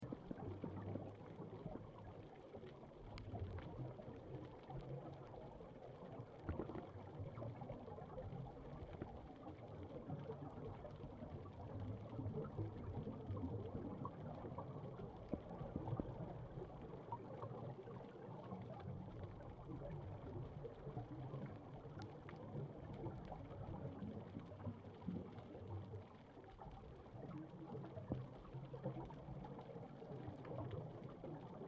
銀帶棘鱗魚 Sargocentron ittodai
台東縣 綠島鄉 中寮港
錄音環境 水下